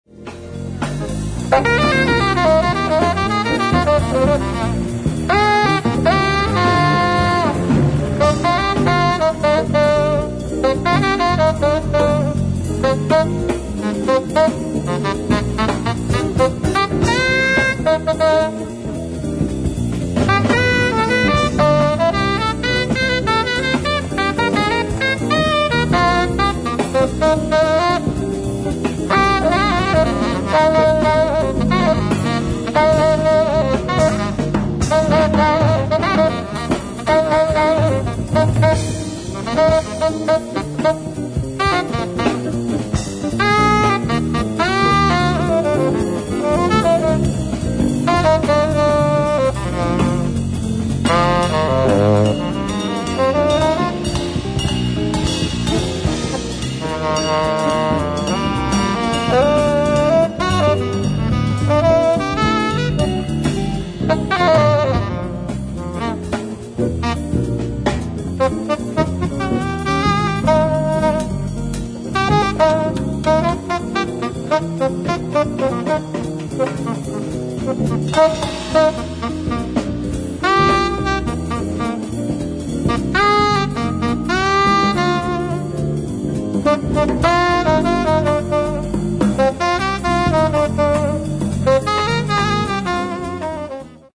ライブ・アット・シャトレ座、パリ・フランス
※試聴用に実際より音質を落としています。